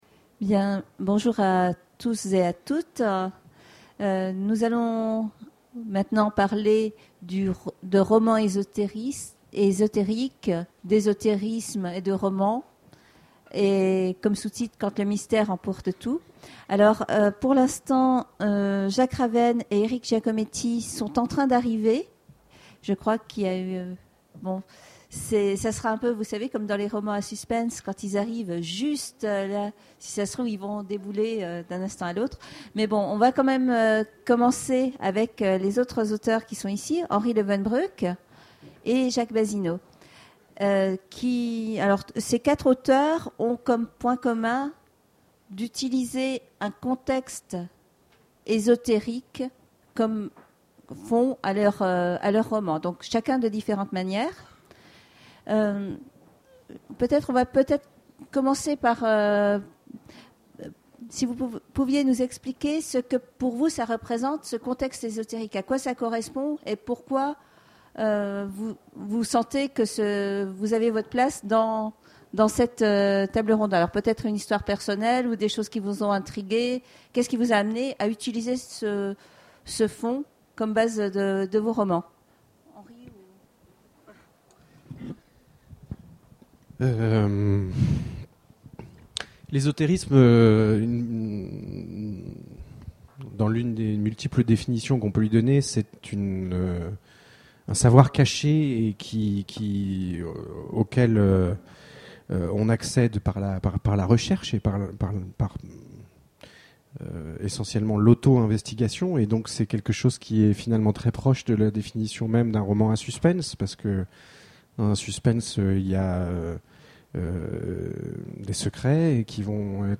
Imaginales 2013 : Conférence Romanesque et ésotérisme